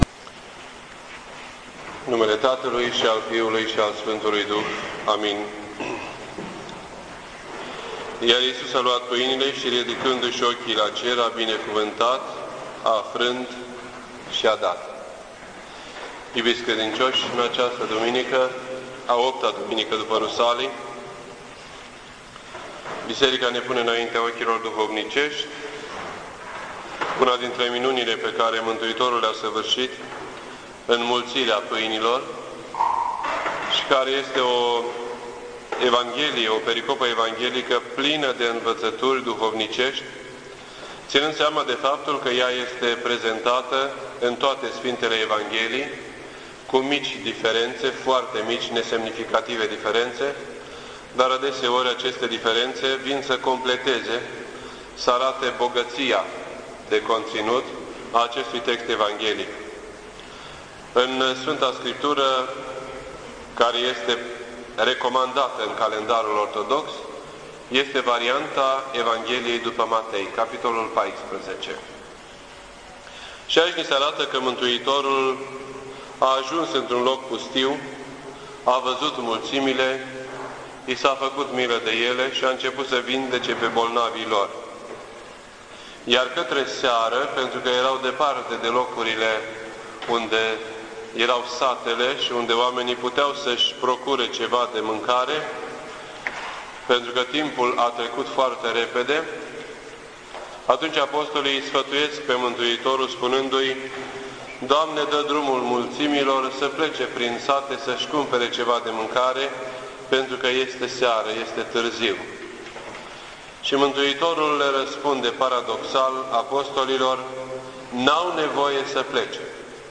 This entry was posted on Sunday, July 22nd, 2007 at 10:14 AM and is filed under Predici ortodoxe in format audio.